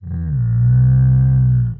食人魔" 呻吟4
描述：食人魔（或其他大型怪物）呻吟的声音
Tag: 呻吟 呻吟 怪物